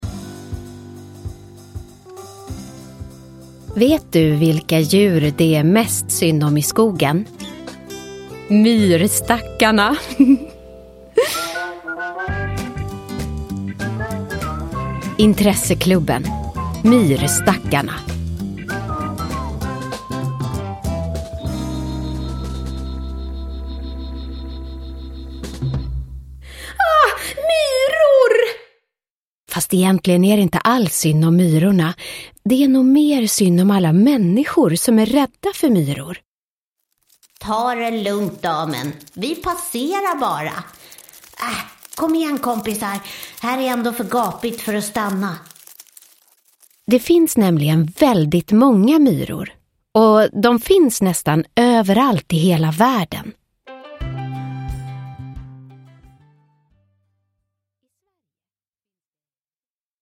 Myrstackarna – Ljudbok – Laddas ner